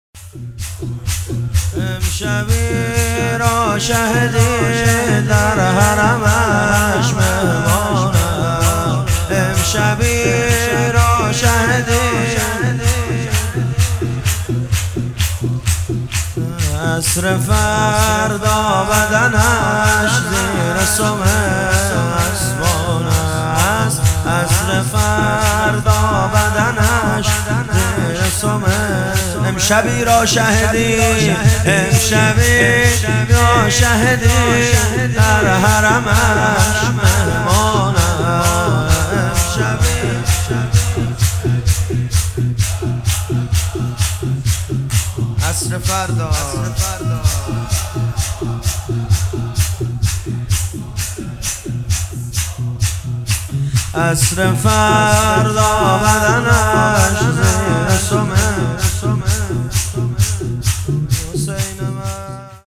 محرم1400 - شب عاشورا - شور - امشبی را شه دین
محرم1400